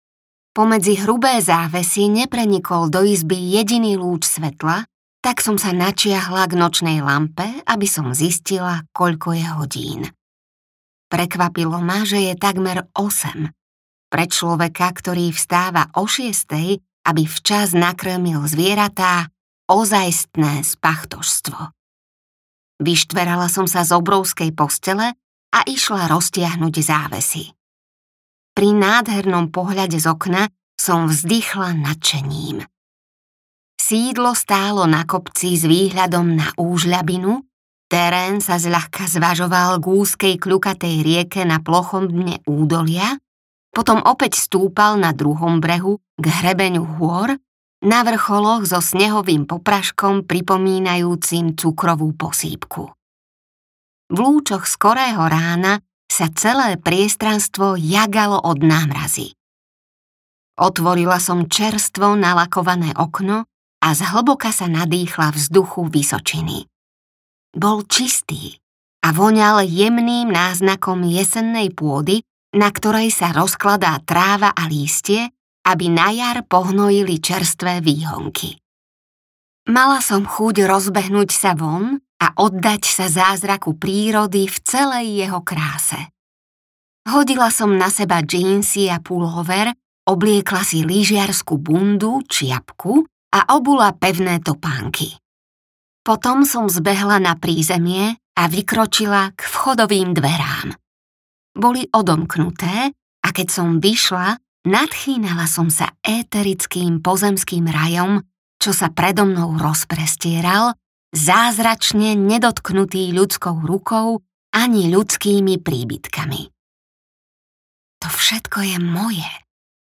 Mesačná sestra audiokniha
Ukázka z knihy
mesacna-sestra-audiokniha